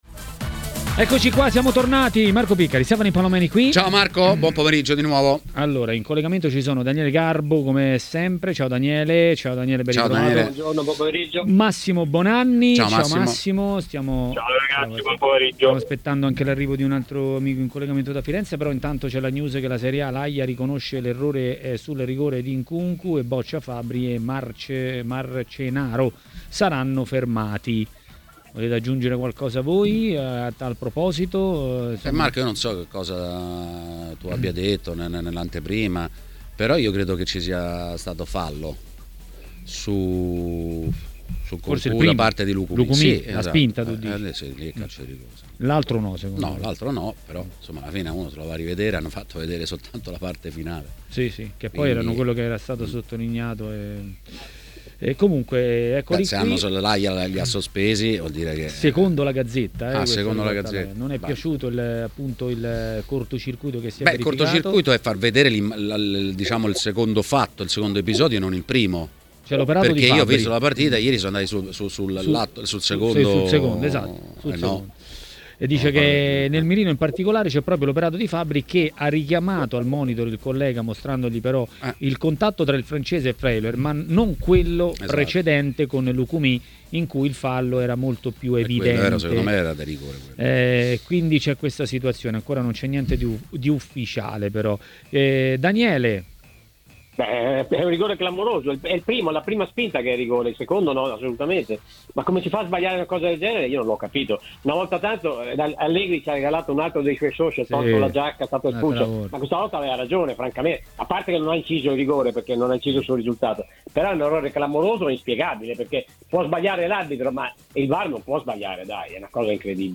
nel pomeriggio di TMW Radio Quanto pesa la sconfitta contro la Juventus per l'Inter?